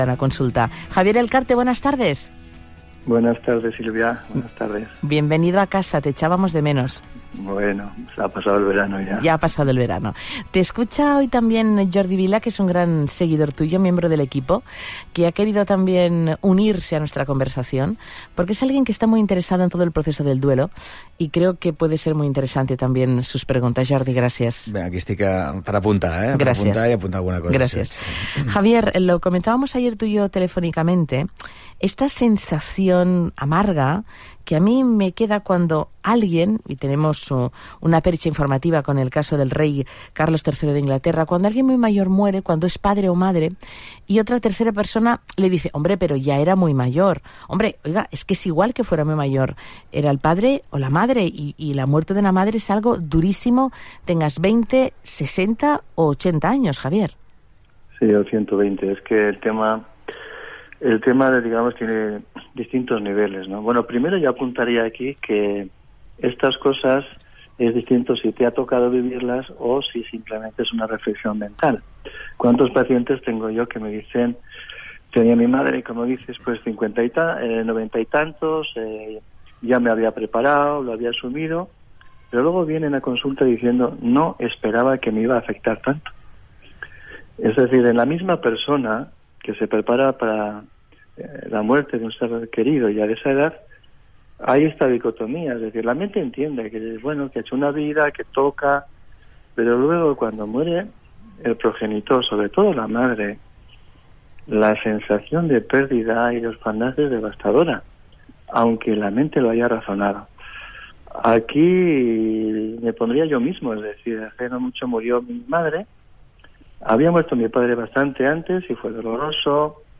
Arrancan este curso, charlando sobre el duelo por la muerte de seres muy queridos.